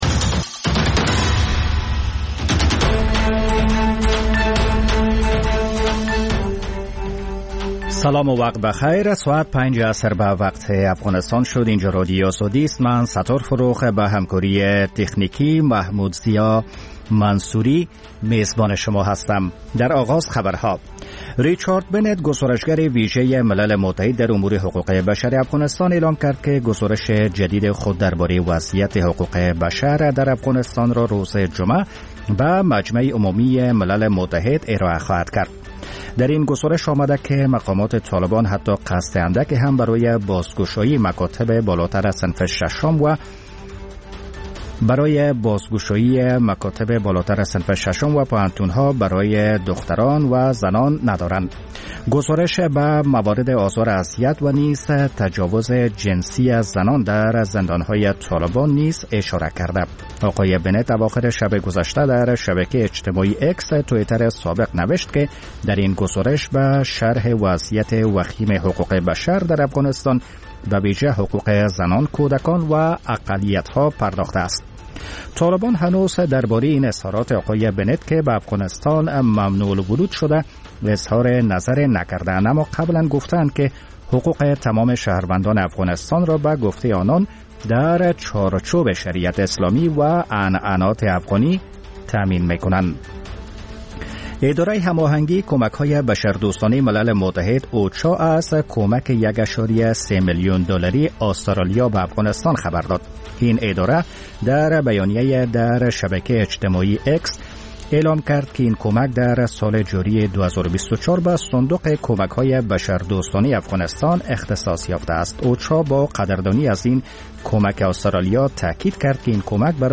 ساعت خبری عصر - ستودیوی P1